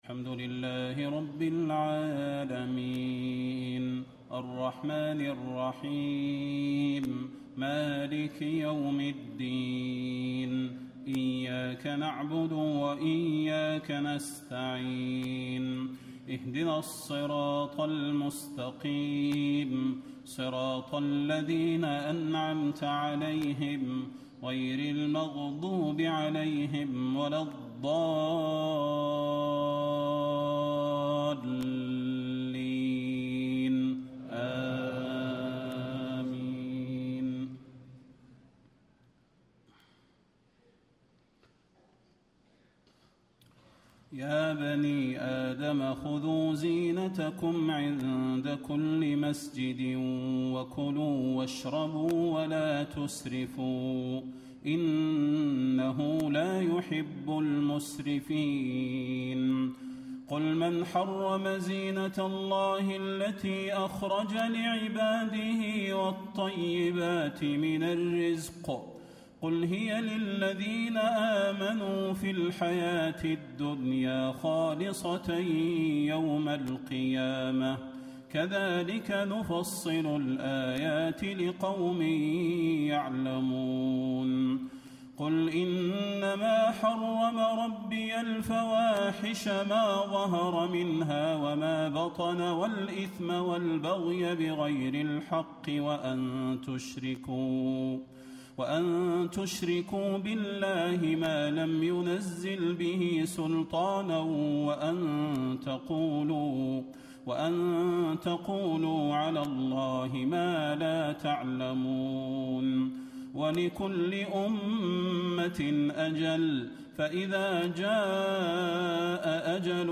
تهجد ليلة 28 رمضان 1435هـ من سورة الأعراف (31-84) Tahajjud 28 st night Ramadan 1435H from Surah Al-A’raf > تراويح الحرم النبوي عام 1435 🕌 > التراويح - تلاوات الحرمين